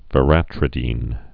(və-rătrĭ-dēn)